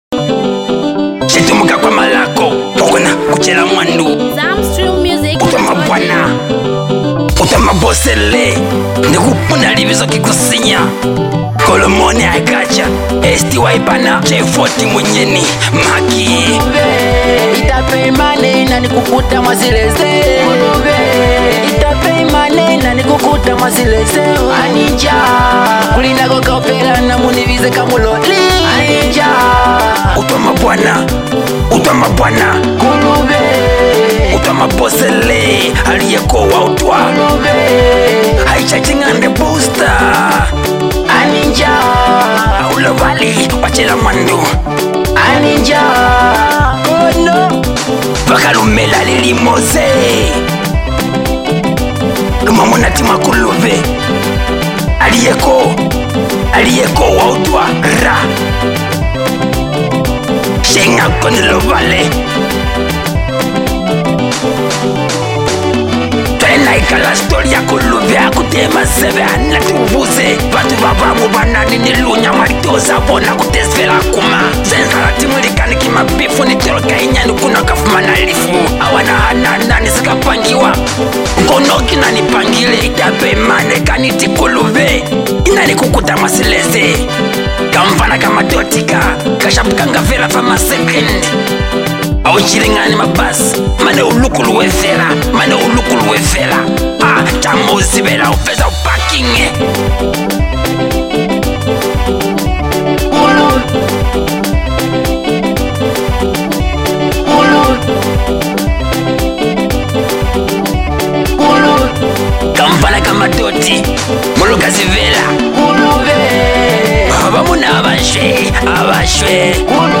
With smooth melodies, relatable lyrics, and rich production
Afro-inspired sounds with local influence